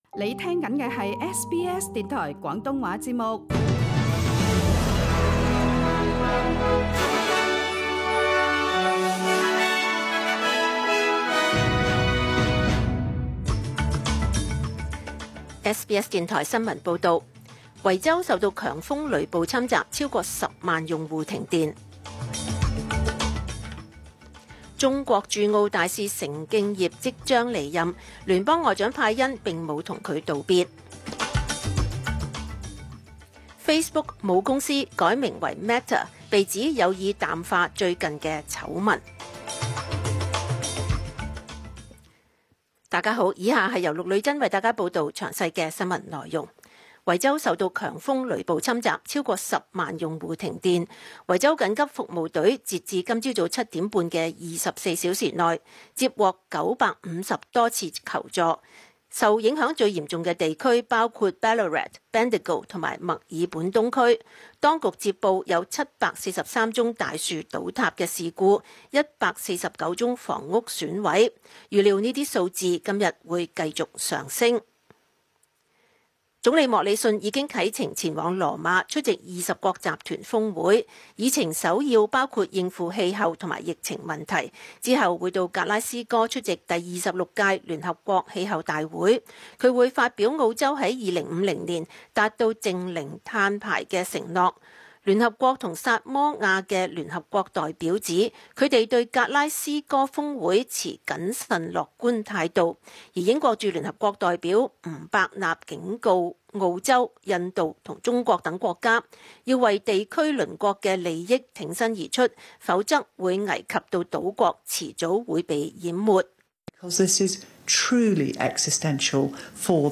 SBS 中文新聞 （十月二十九日）
SBS 廣東話節目中文新聞 Source: SBS Cantonese